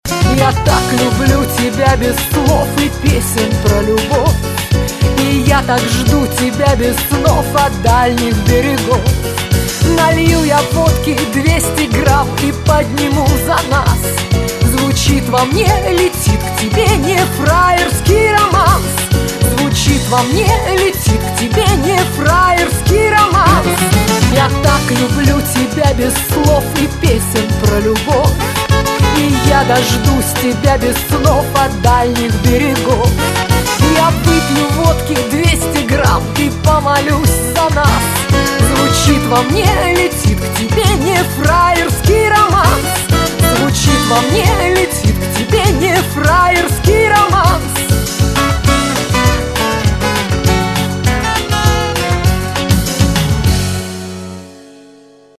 Нарезки шансона